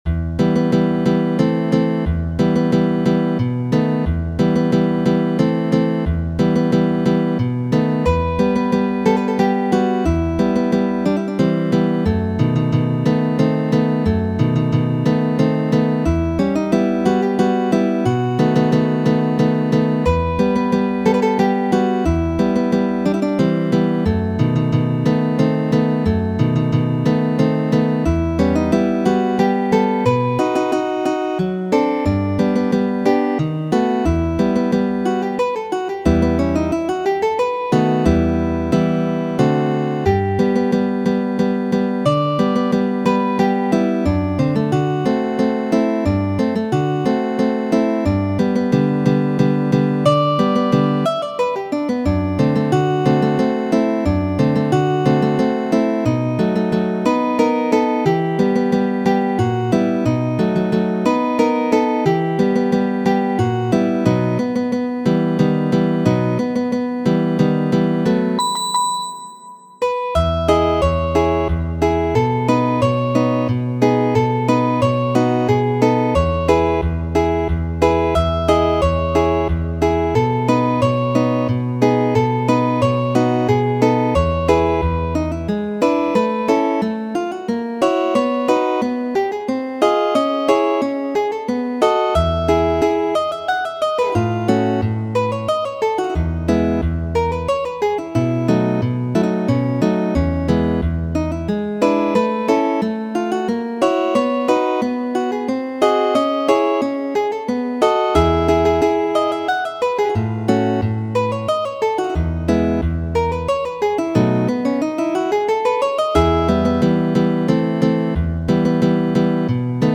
Muziko:
Bolero, De Antonio Cano, komponita en 1850.